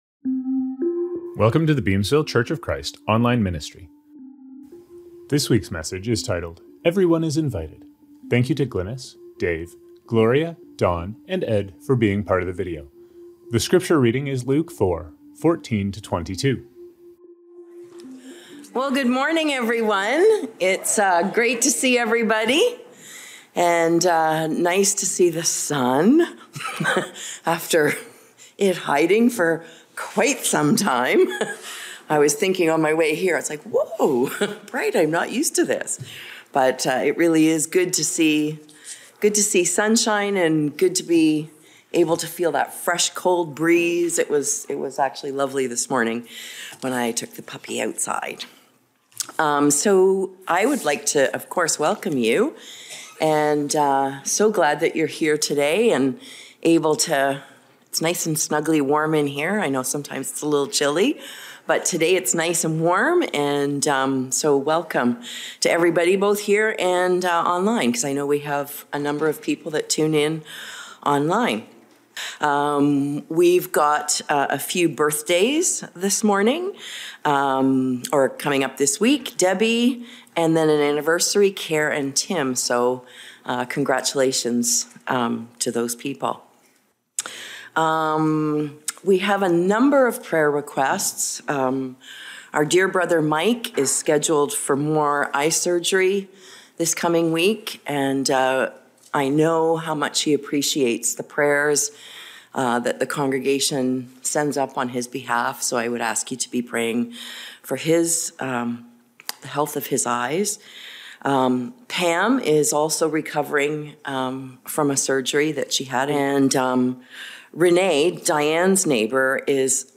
Scriptures from this service: Communion - 1 Corinthians 11:24.